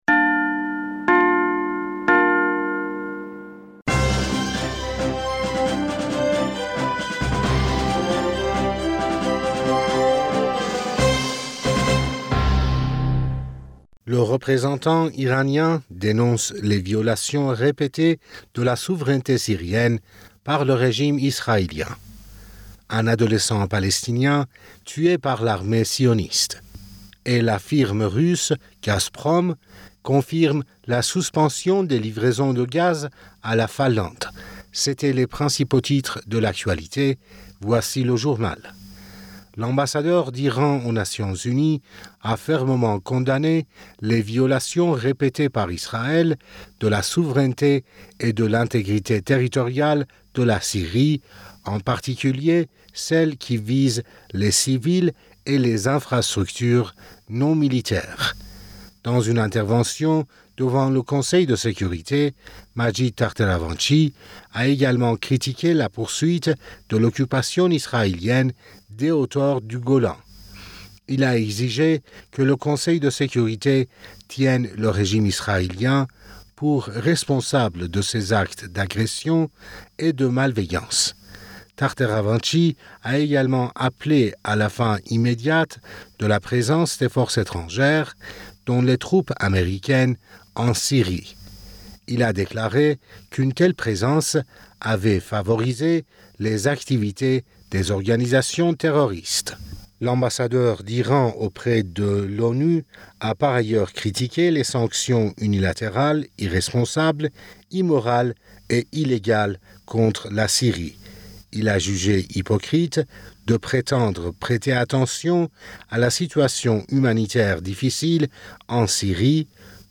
Bulletin d'information Du 21 Mai 2022